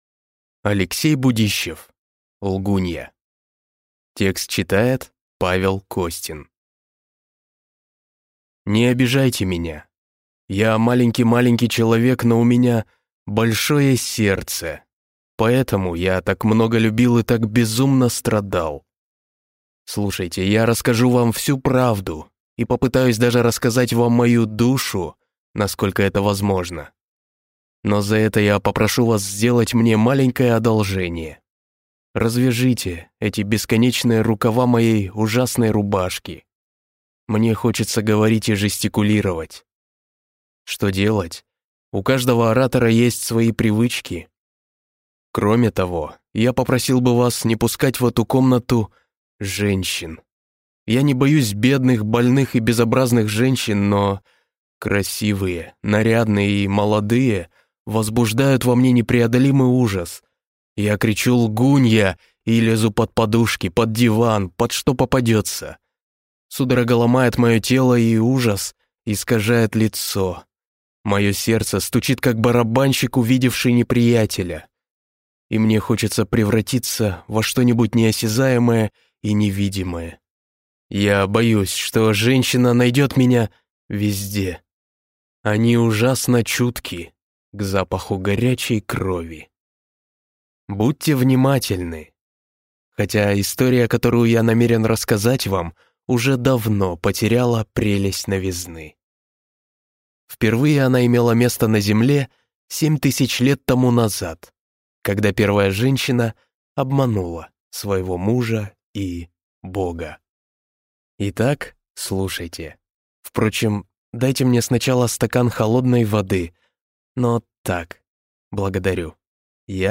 Аудиокнига Лгунья | Библиотека аудиокниг